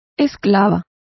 Also find out how esclavas is pronounced correctly.